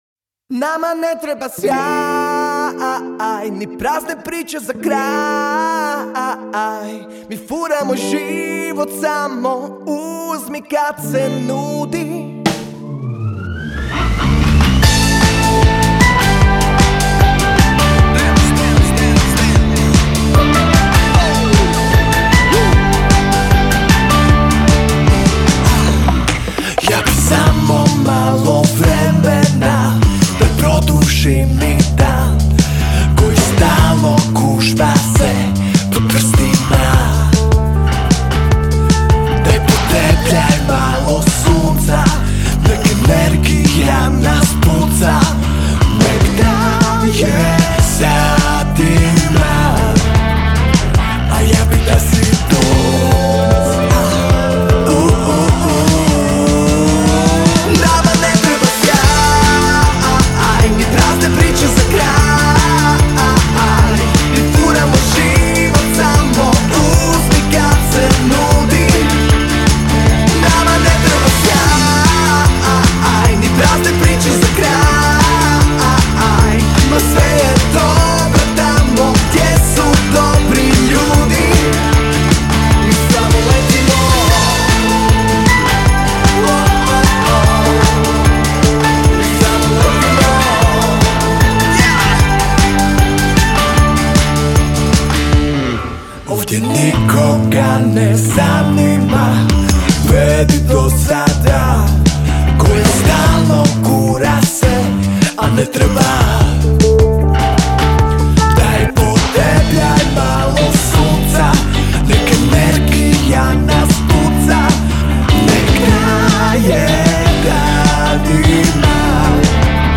Žanr Pop